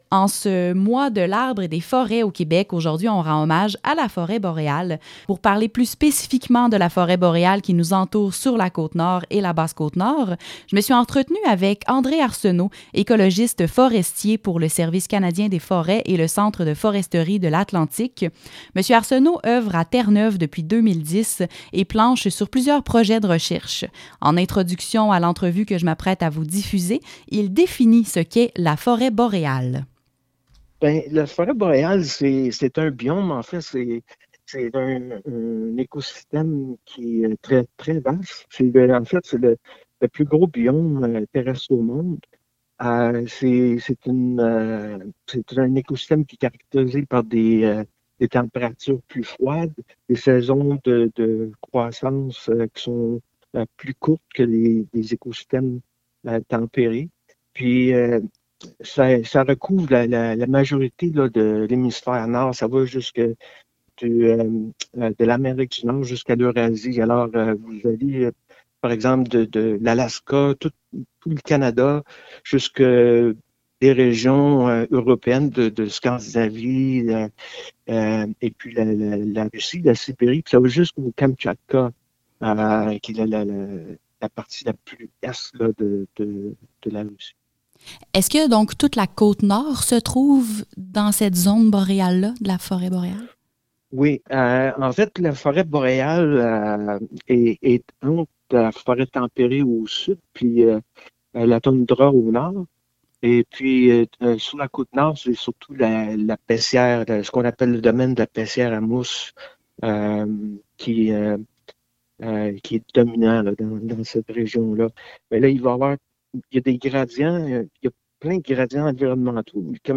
entrevue
Foret-boréale-segment-radio.mp3